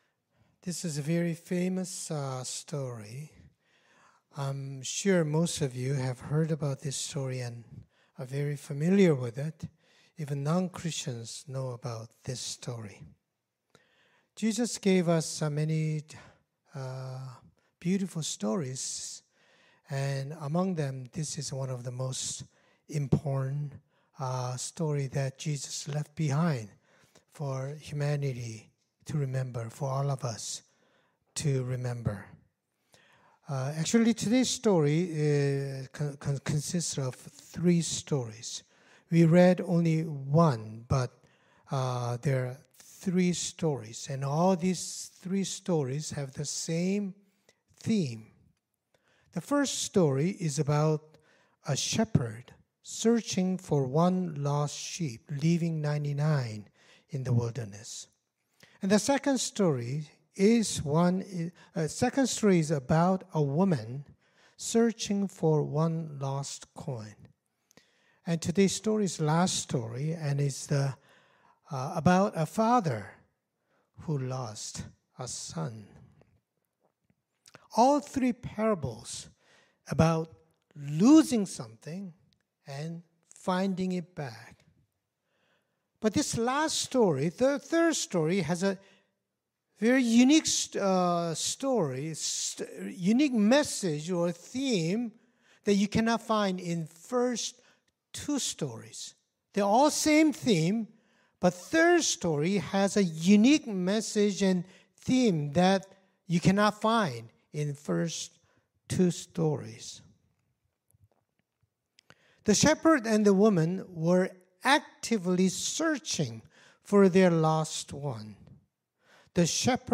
Scripture Passage Luke 15:1-3, 11b-32 Worship Video Worship Audio Sermon Script This is a very famous story.